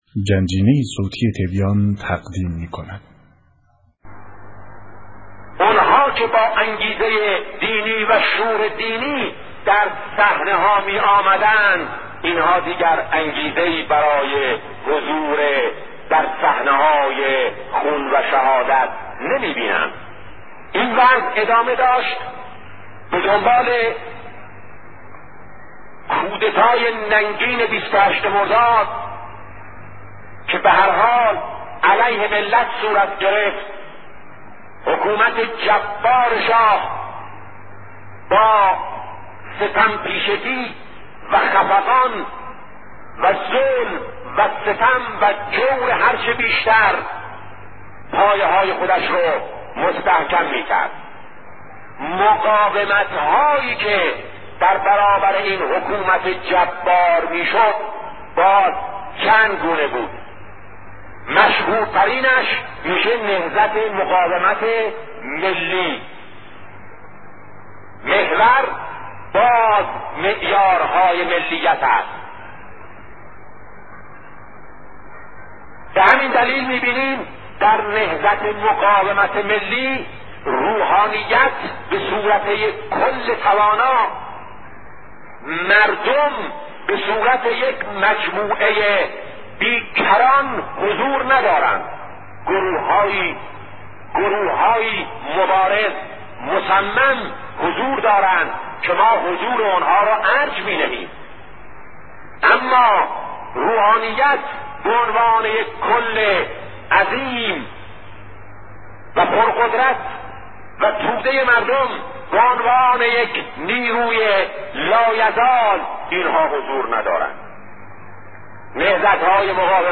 بیانات بزرگان